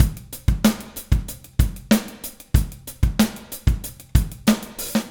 Trem Trance Drums 01 Fill.wav